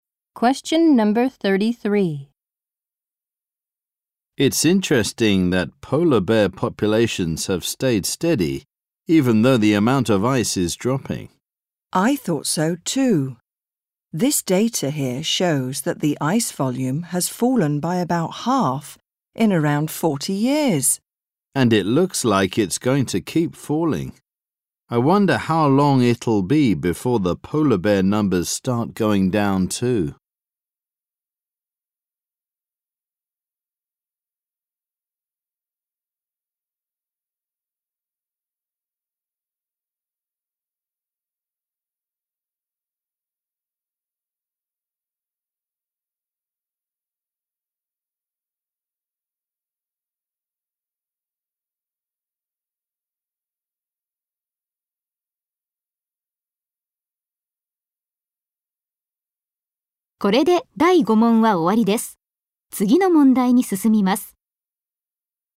○共通テストの出題音声の大半を占める米英の話者の発話に慣れることを第一と考え，音声はアメリカ（北米）英語とイギリス英語で収録。
第5問　問33（アメリカ（北米）英語＋イギリス英語）